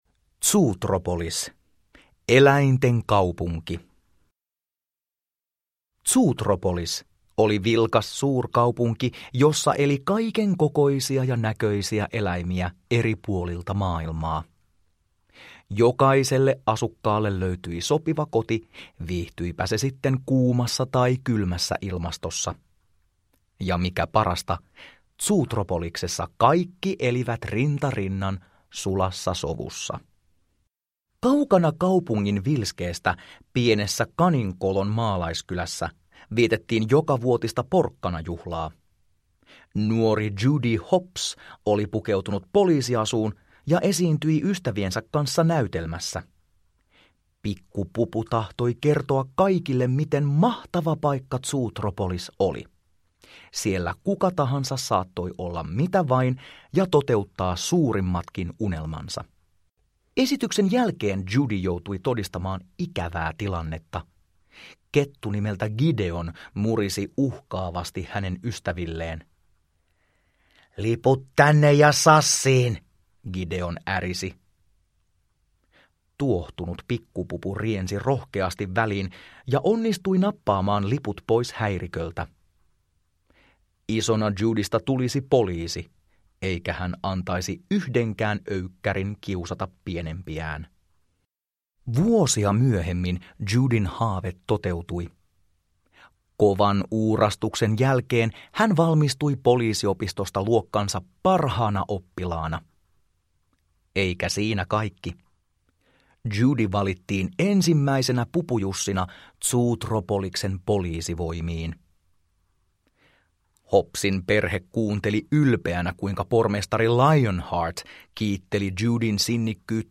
Zootropolis – Ljudbok